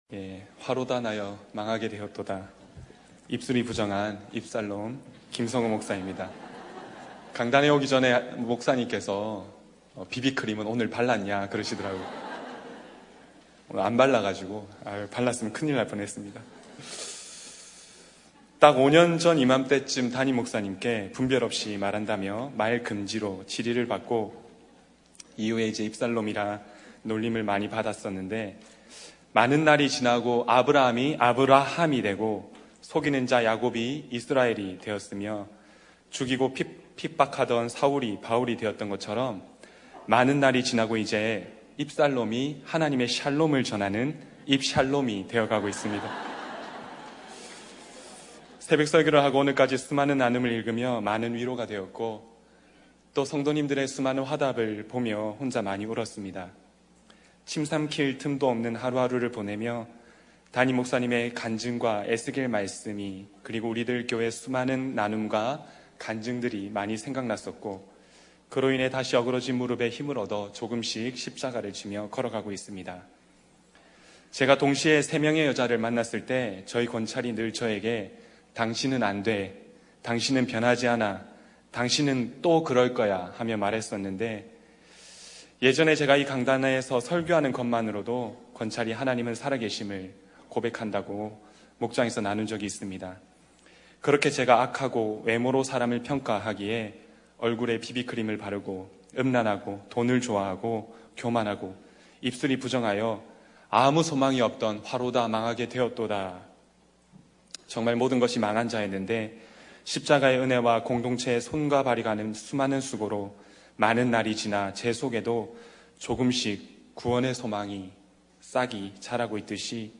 GMAN 라디오 설교방송